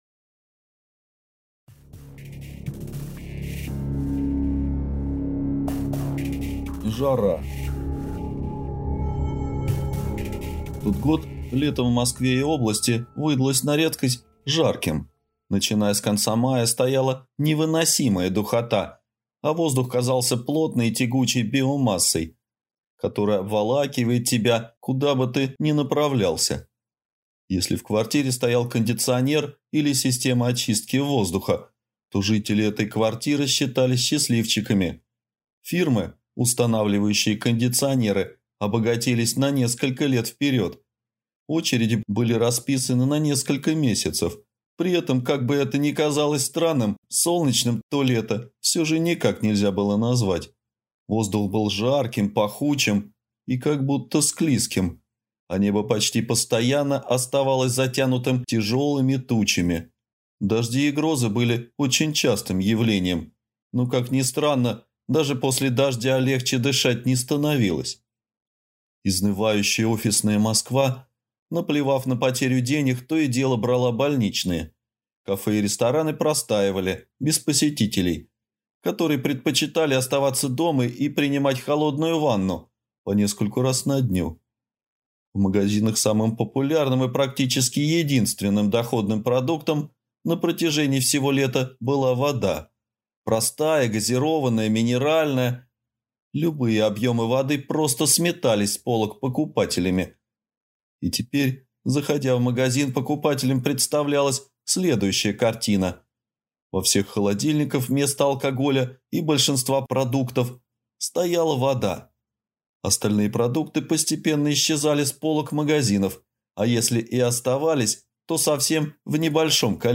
Аудиокнига Конец Антропоцена | Библиотека аудиокниг